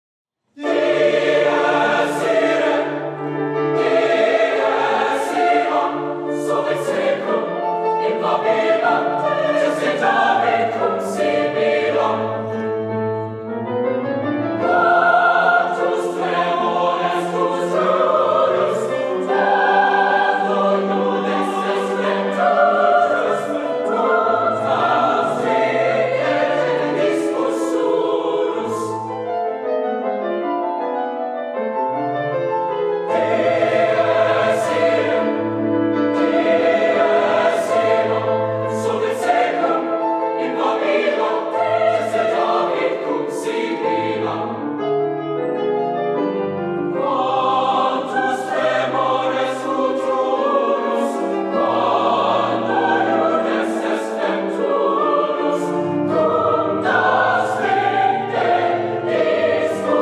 four curricular choirs and two a cappella ensembles
Sing With Heart: Spring Concert, 2019
With: Choral Union